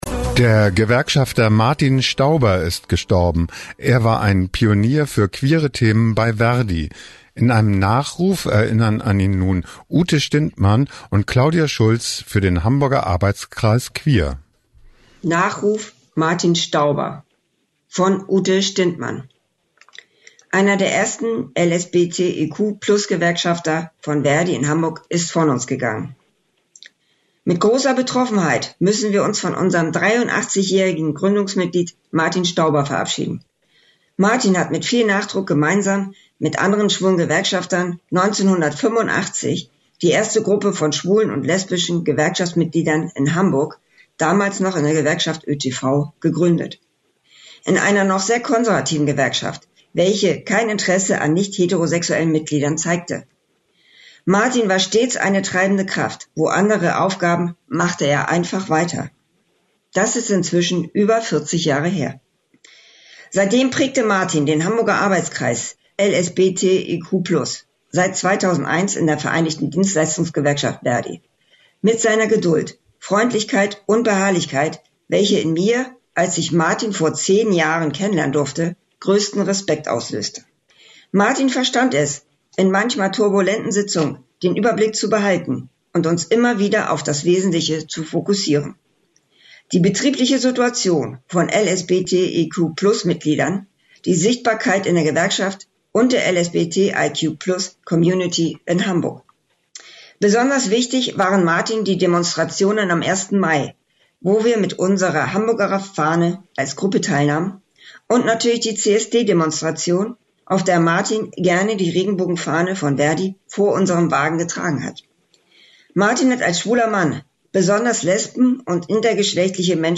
Er wurde 83 Jahre alt. Ein Nachruf